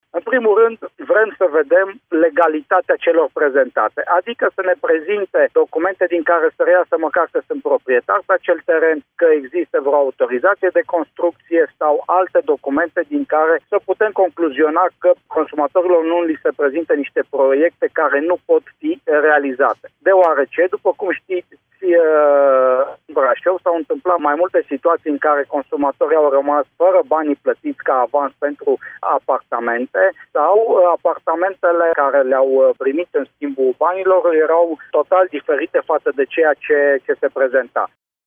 Comisarul şef al Comisariatului Regional pentru Protecţia Consumatorului Braşov, Sorin Susanu: